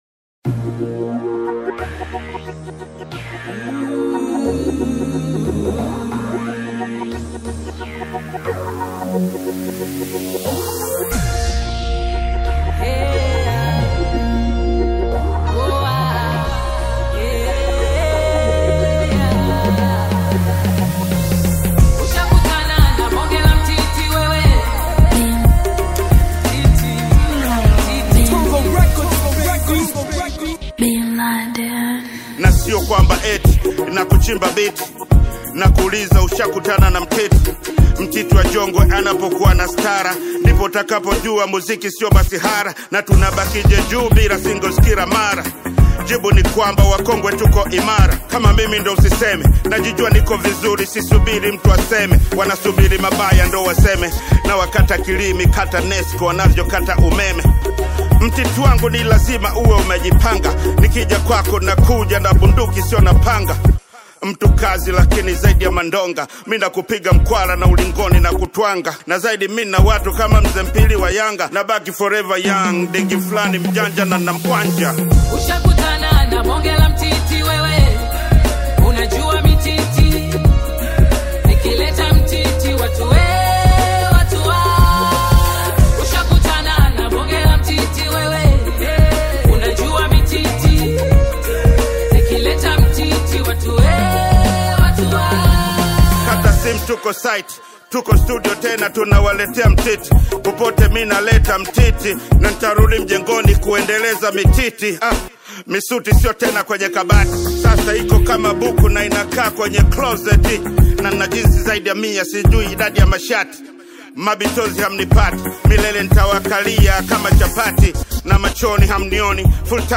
Tanzanian veteran bongo flava artist
African Music